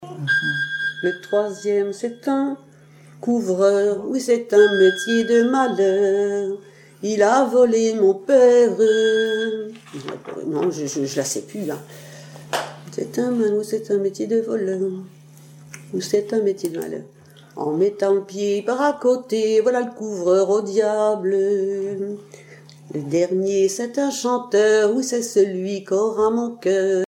Genre énumérative
Chansons et commentaires
Pièce musicale inédite